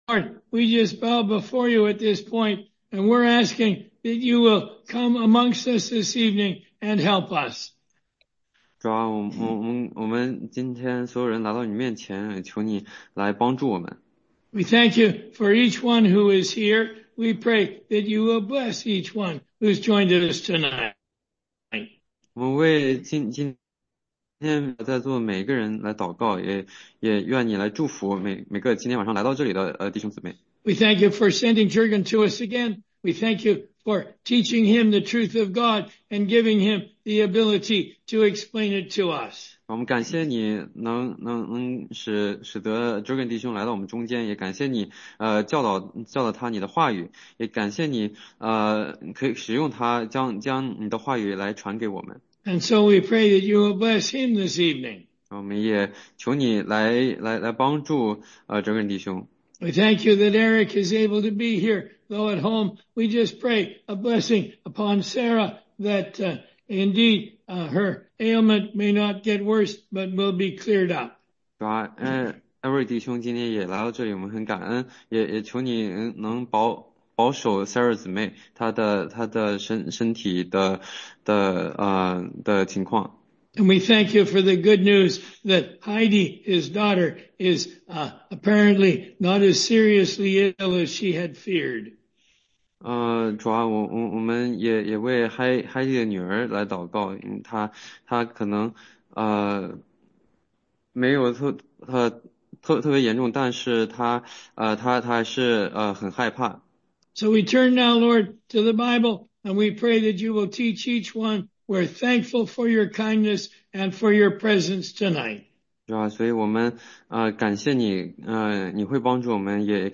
16街讲道录音
中英文查经